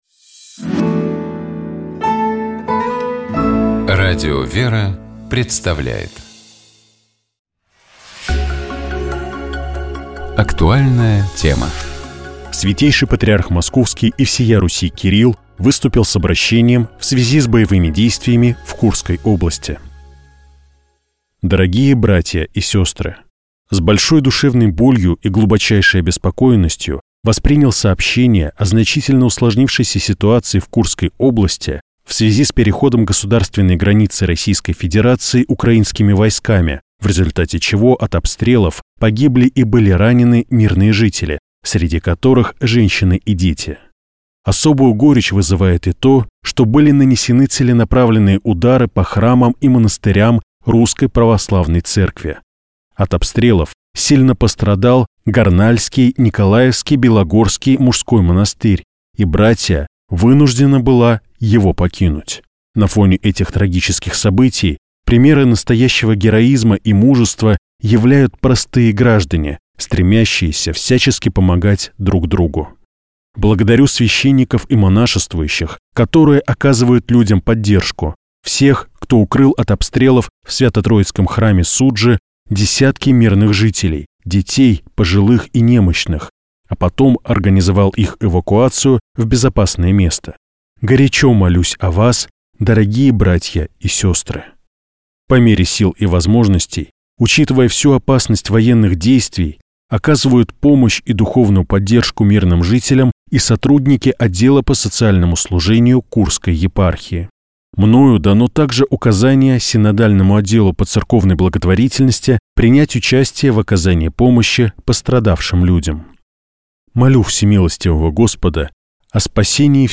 Обращение Святейшего Патриарха Кирилла в связи с боевыми действиями в Курской области
Святейший Патриарх Московский и всея Руси Кирилл выступил с обращением в связи с боевыми действиями в Курской области.